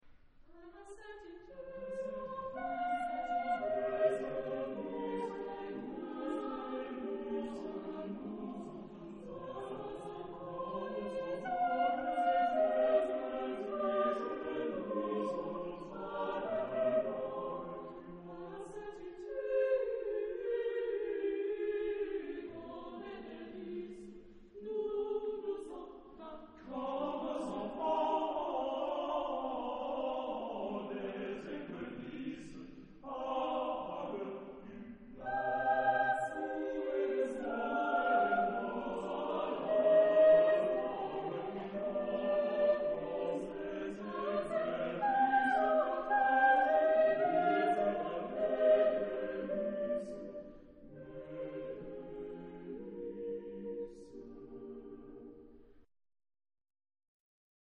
Genre-Style-Forme : contemporain ; Chanson ; Profane
Type de choeur : SATB  (4 voix mixtes )
Tonalité : fa mineur